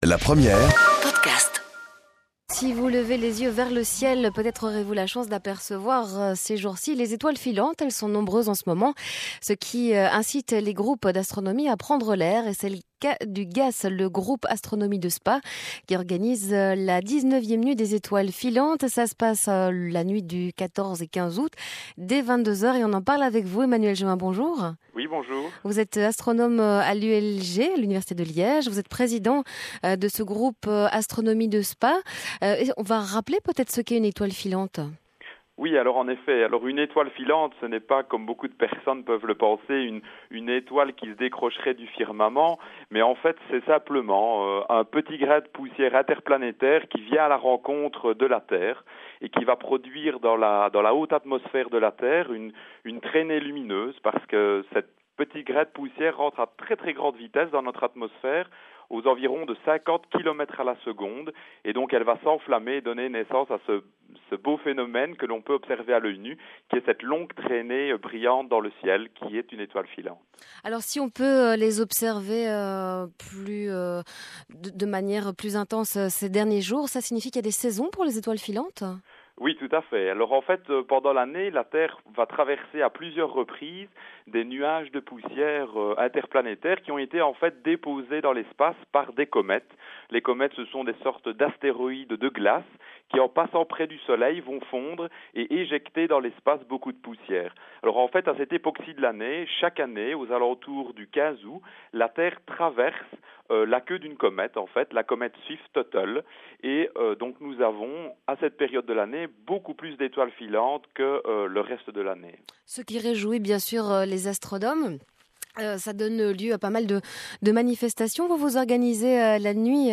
Les Nuits des Etoiles Filantes – Interview